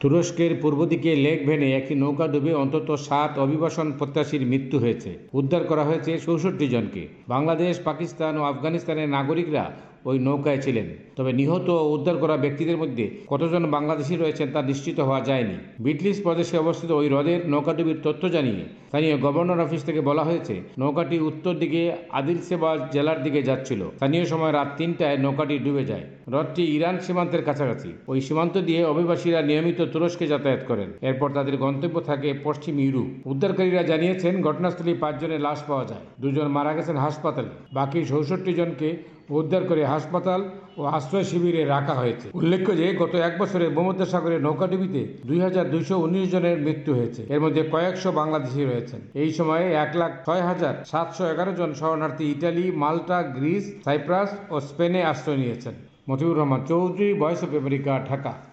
ঢাকা থেকে
রিপোর্ট।